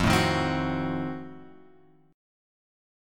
F7b9 chord {1 0 1 x 1 2} chord